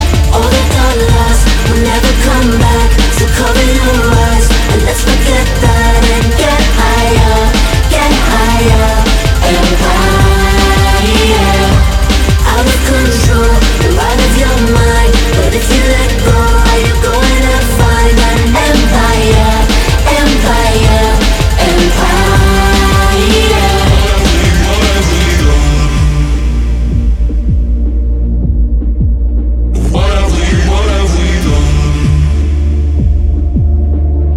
• Качество: 320, Stereo
Electronic
drum n bass